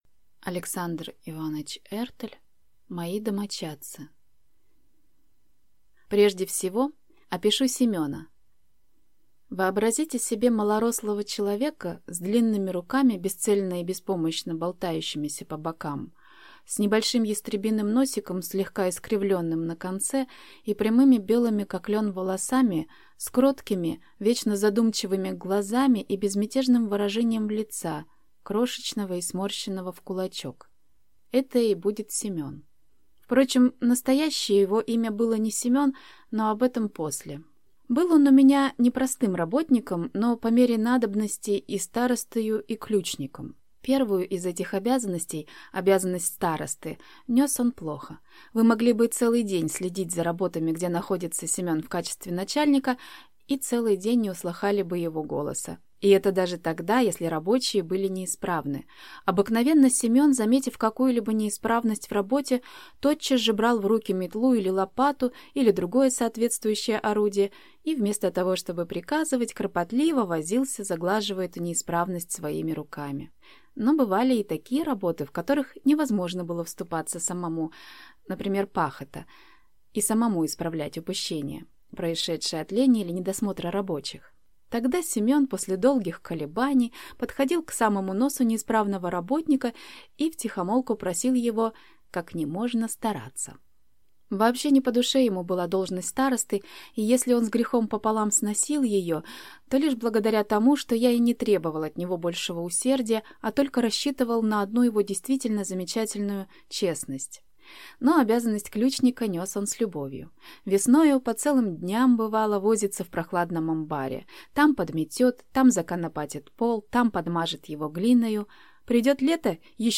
Аудиокнига Мои домочадцы | Библиотека аудиокниг